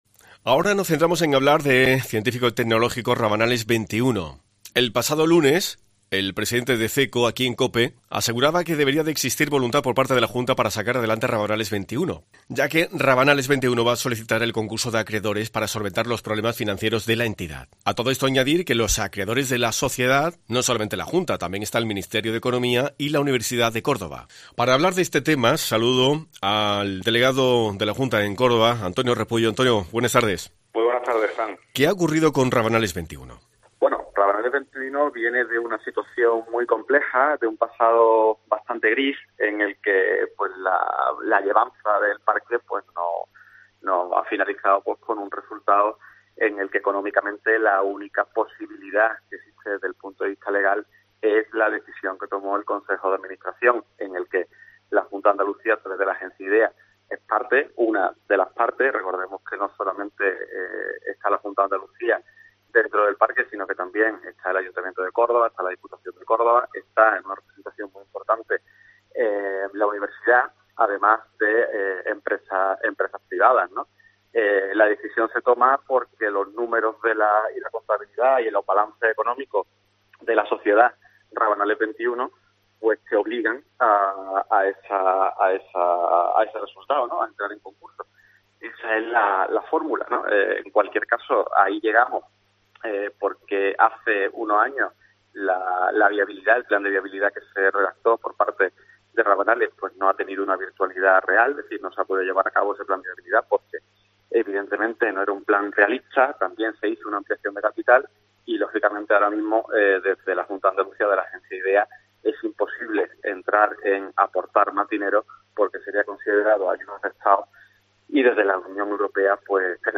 Este miércoles Antonio Repullo, Delegado de la Junta en Córdoba, aclaró la postura del ente regional en COPE Córdoba.